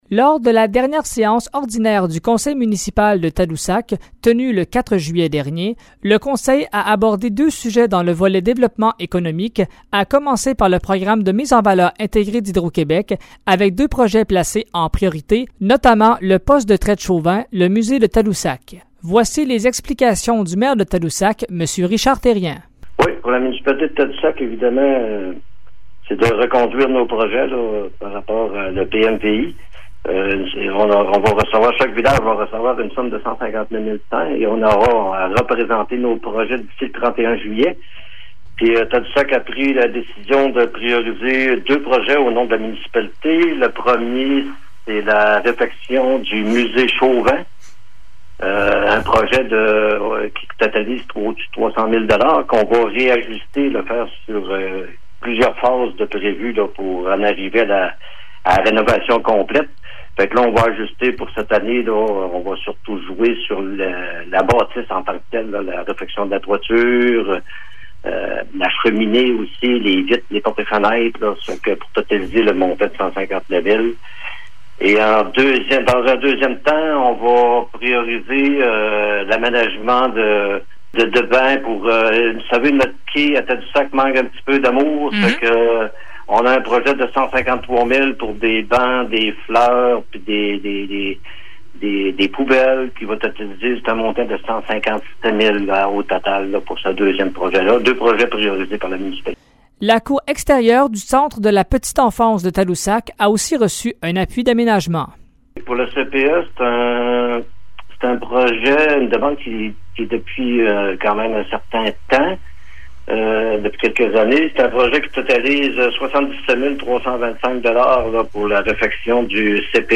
Voici le reportage